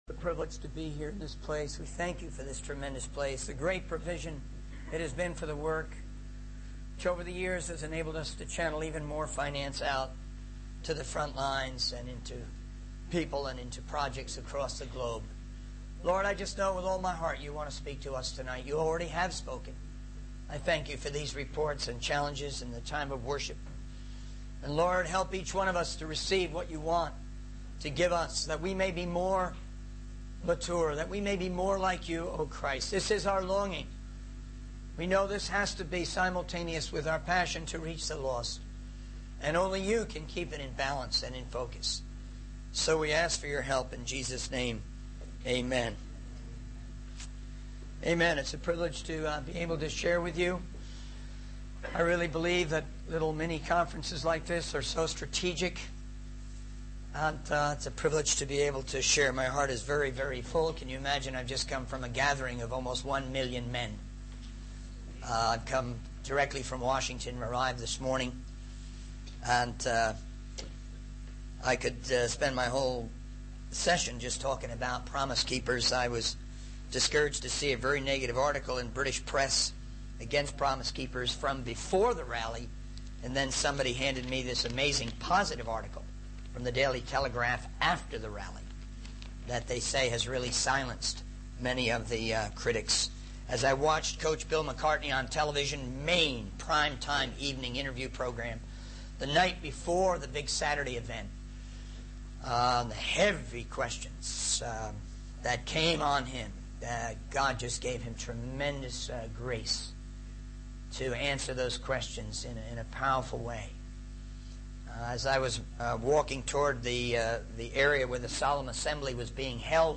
In this sermon, the speaker reflects on his experience attending a six-hour event focused on prayer, the word of God, repentance, and some praise and worship.